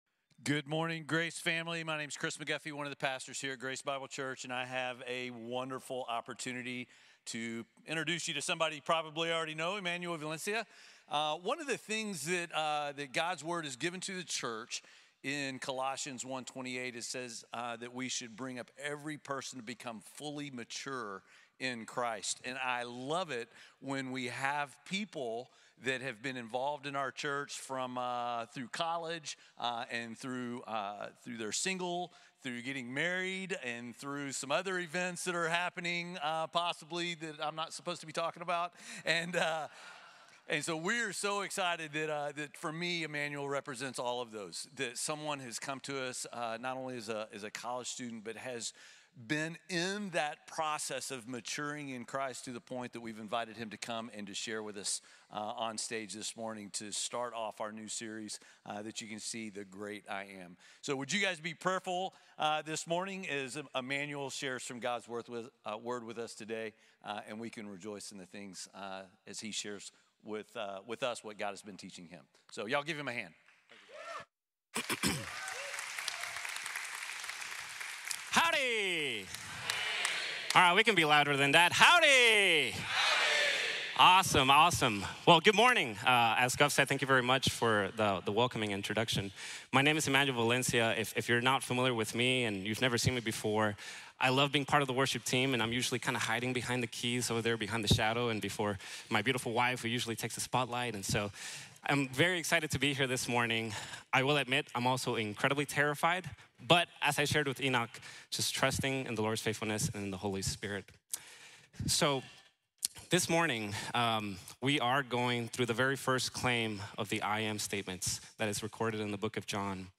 YO SOY el Pan de Vida | Sermon | Grace Bible Church